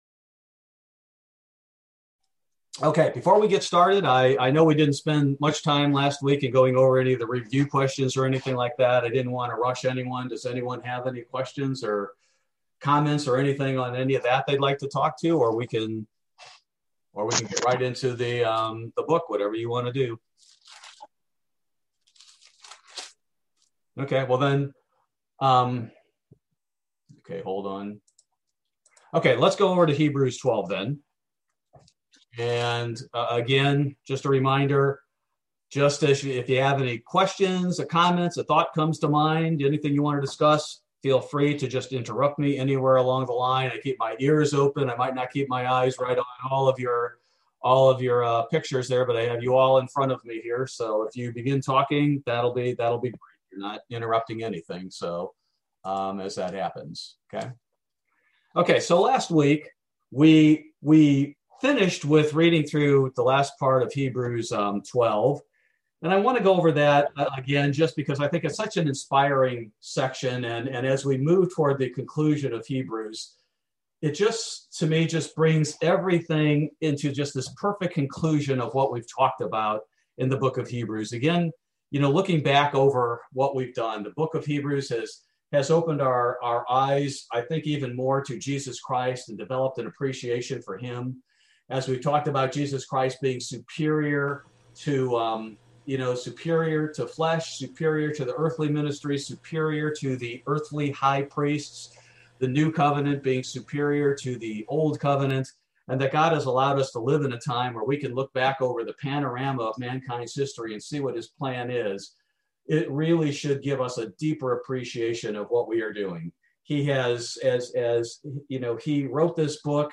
Bible Study: March 10, 2021